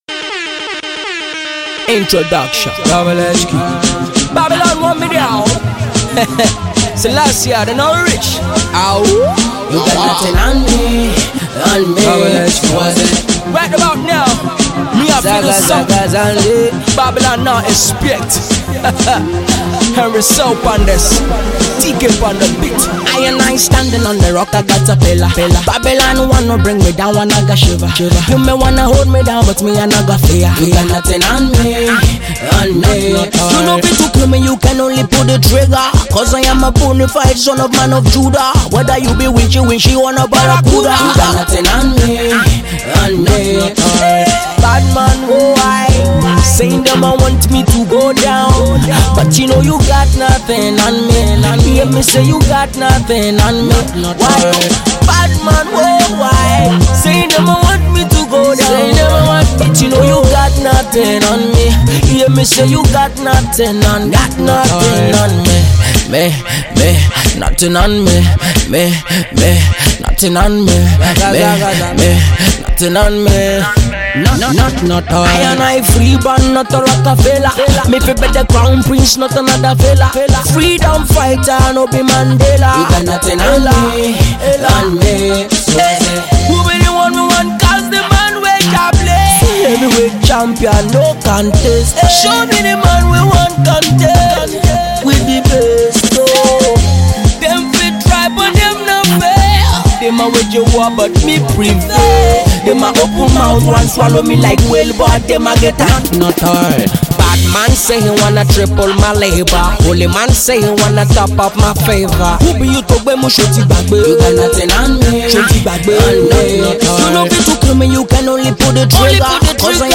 reggae track